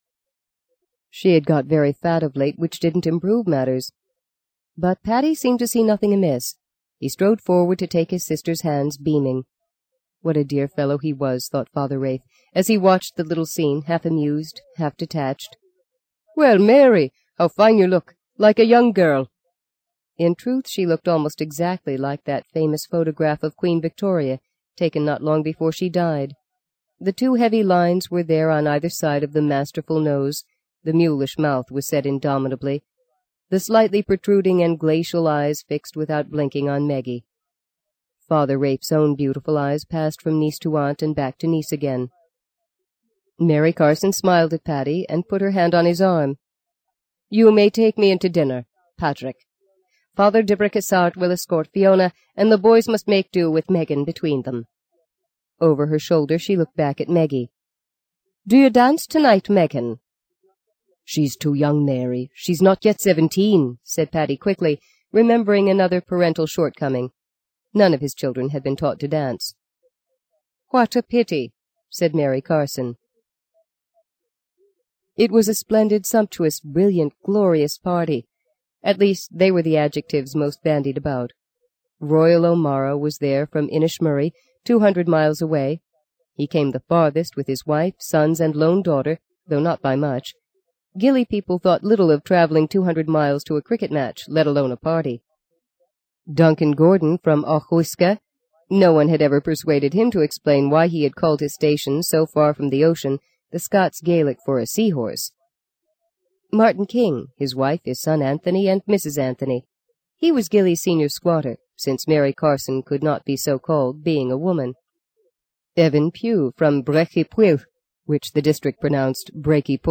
在线英语听力室【荆棘鸟】第七章 02的听力文件下载,荆棘鸟—双语有声读物—听力教程—英语听力—在线英语听力室